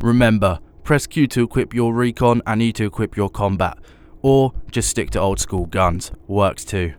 Voice Lines
Update Voice Overs for Amplification & Normalisation
remember press Q to equip your recon and E to equip your combat.wav